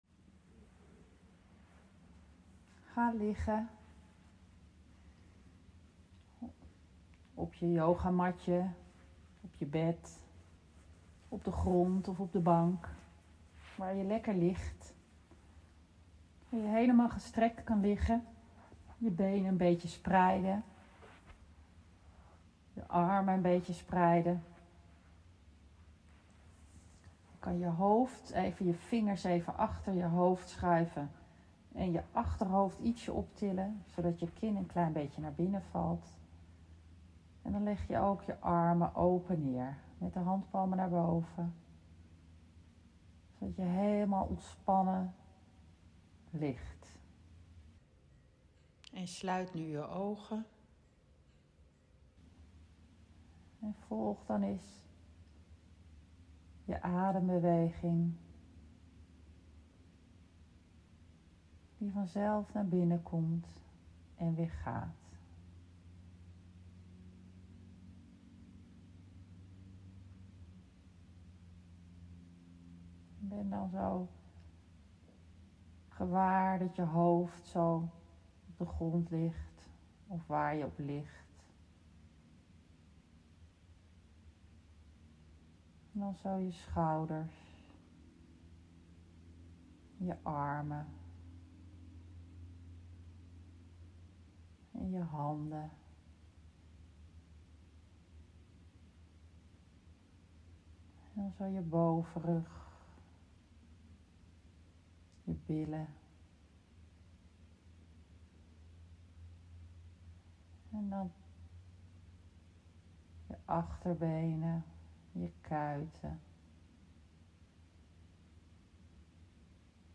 Adembeweging.mp3